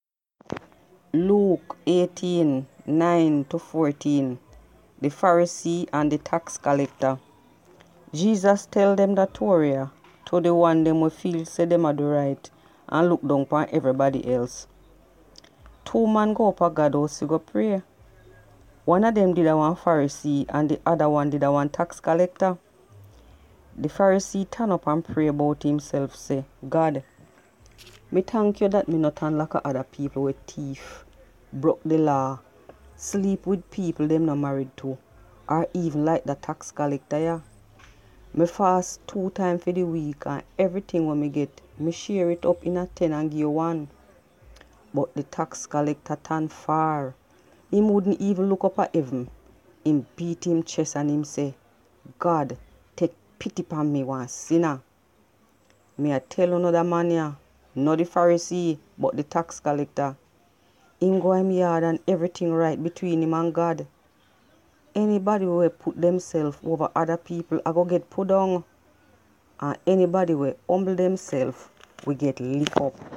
Patois Bible - Luke 18. 9-14.mp3